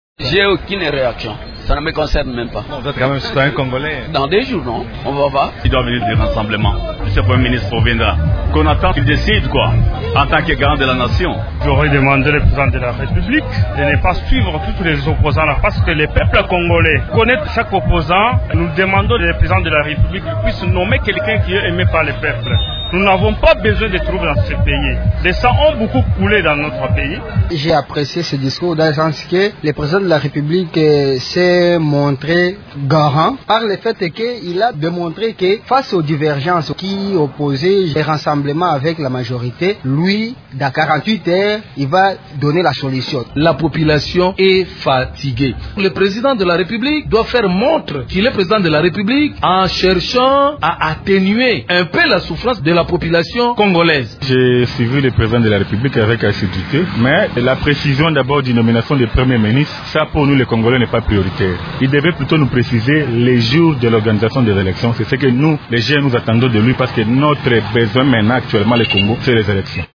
Voici les réactions des habitants de Kinshasa et de Kisangani, après le discours du chef de l'Etat.
kin_vox_pop_adresse_kabila-00.mp3